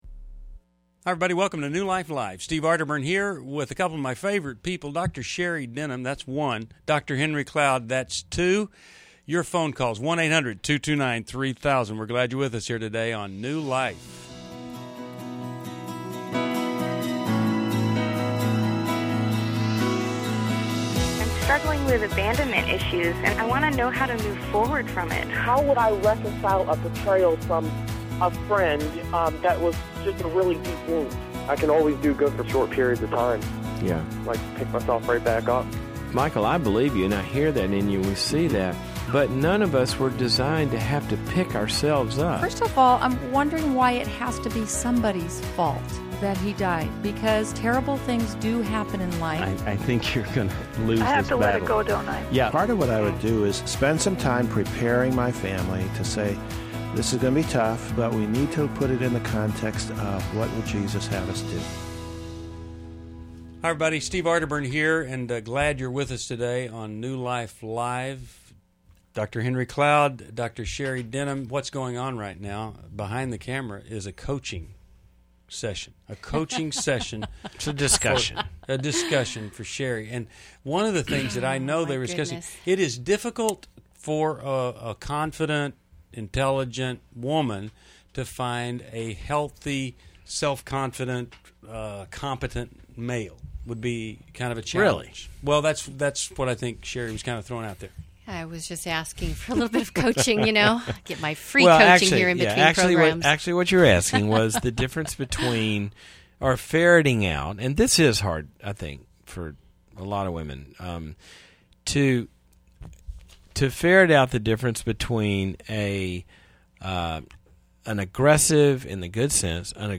Caller Questions: 1.